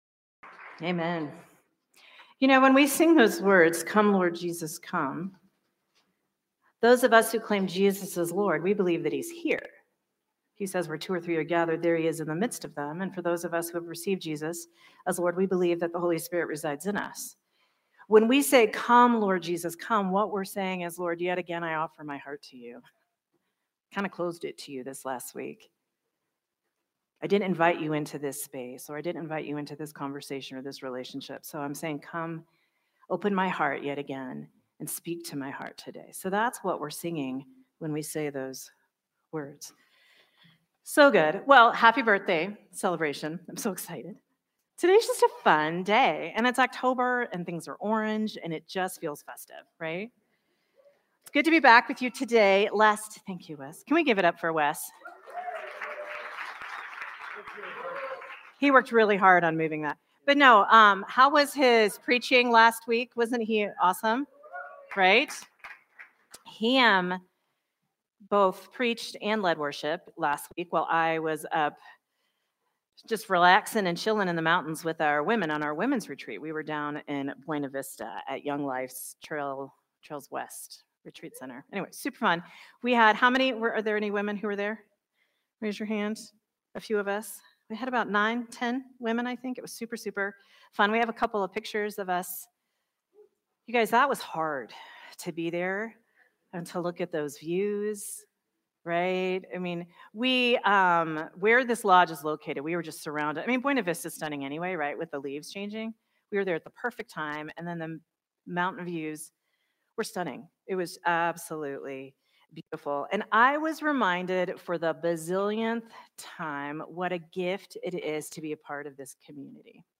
Sermon from Celebration Community Church on October 5, 2025